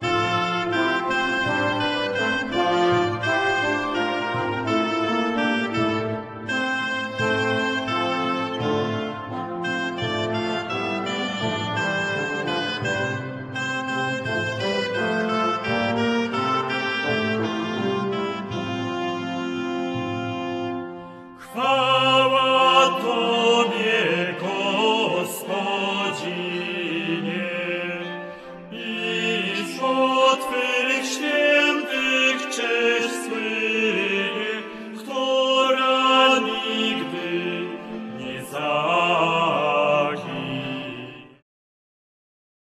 lira korbowa, psałterium, bęben, viola da gamba